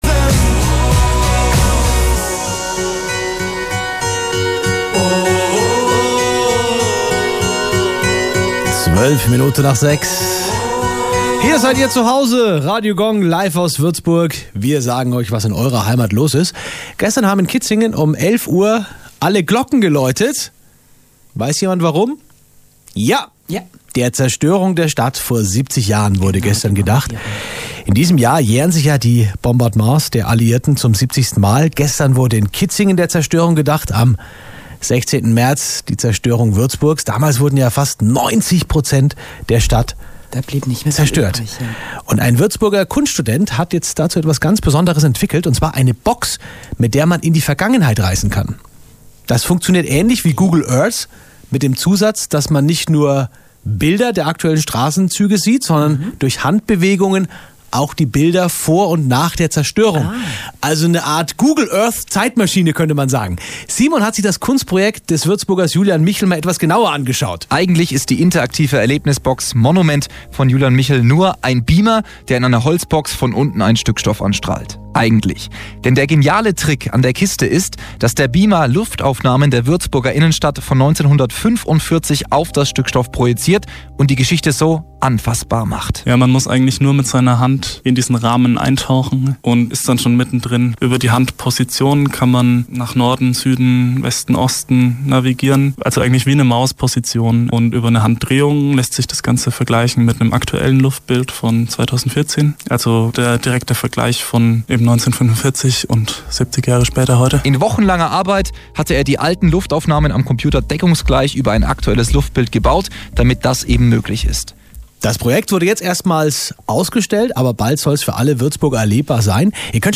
24.2.2015 Interview bei